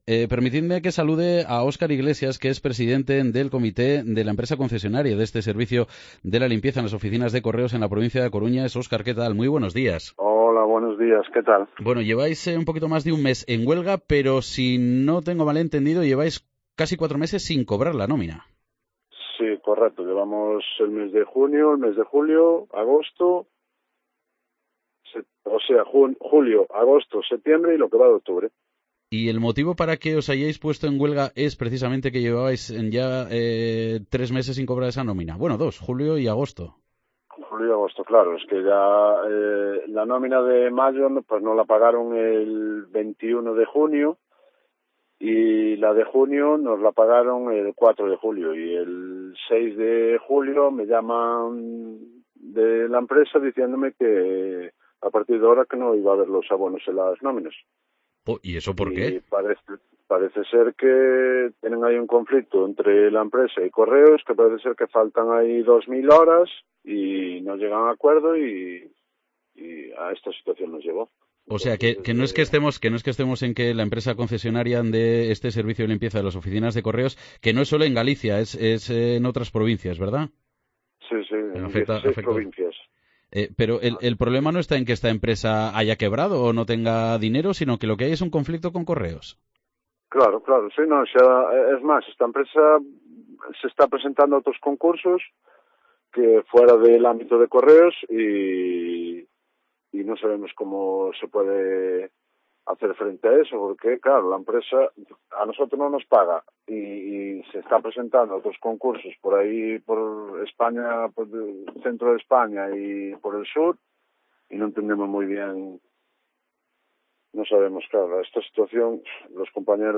Santiago - Publicado el 13 oct 2023, 17:34 - Actualizado 13 oct 2023, 17:38